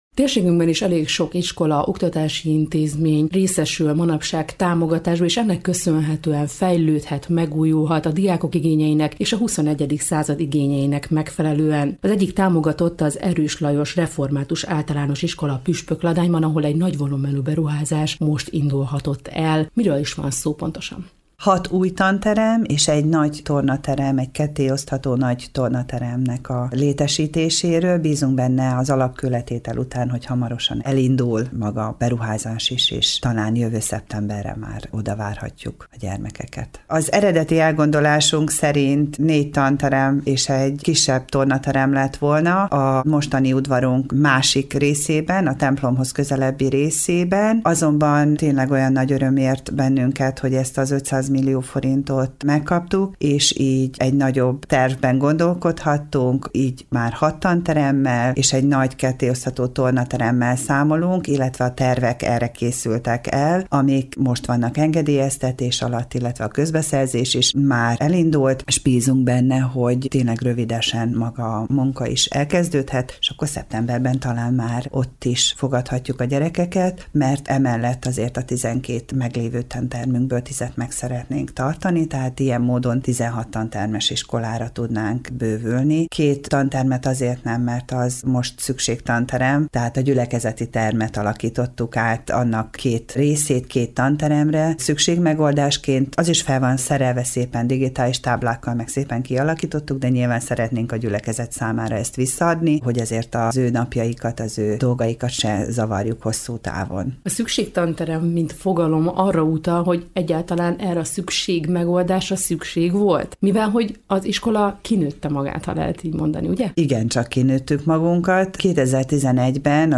kérdezte az Európa Rádió ban: